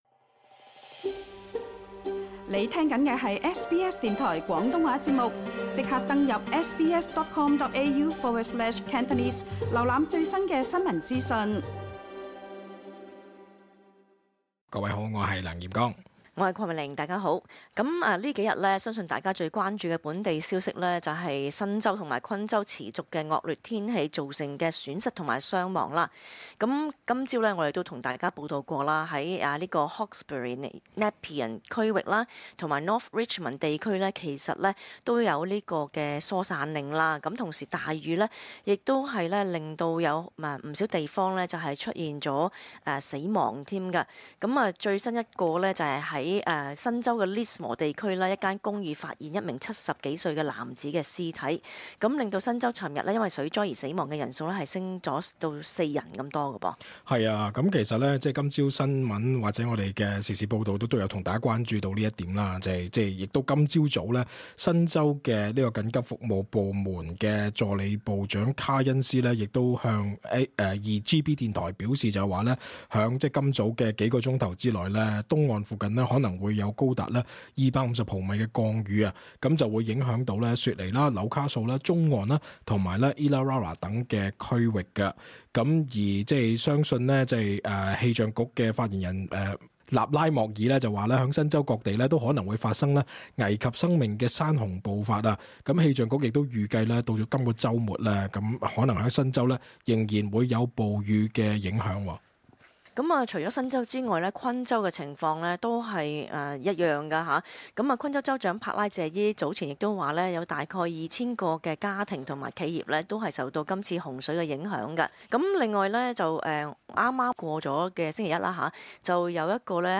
cantonese-_talkback_-_qld_-_final_-march_3.mp3